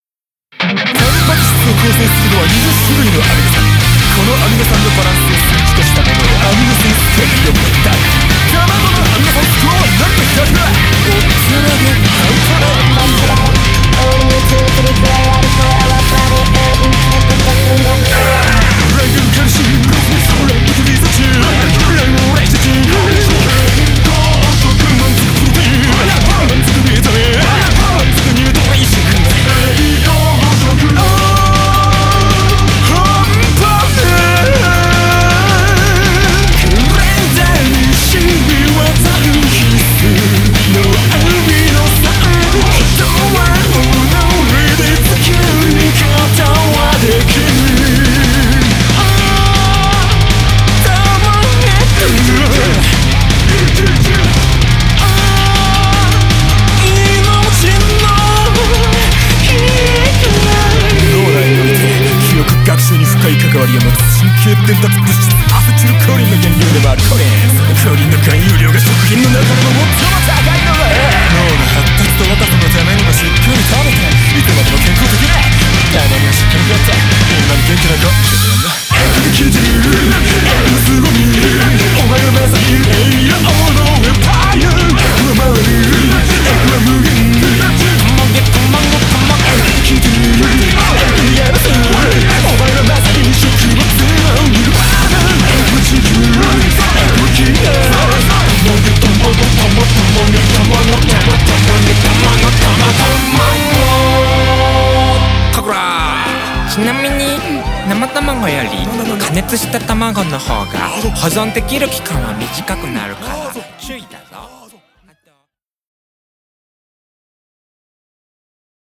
BPM107-160